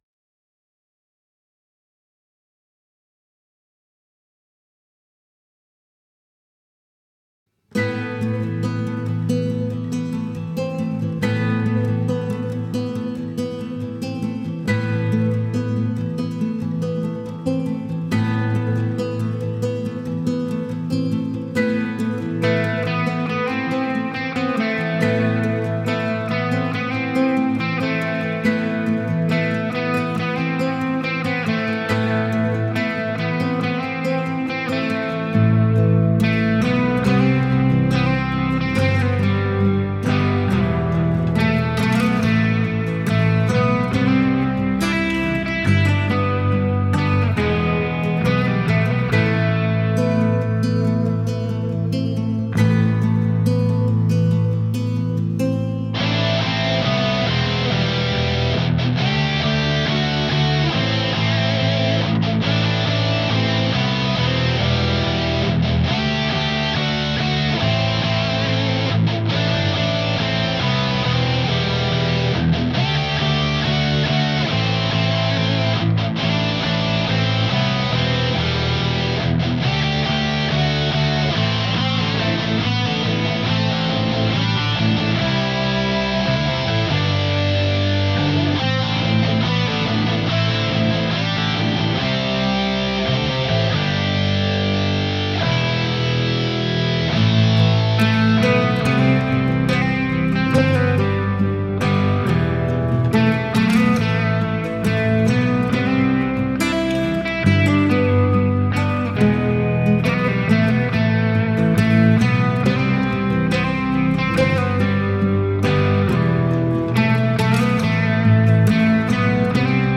This time I also recorded the bass part.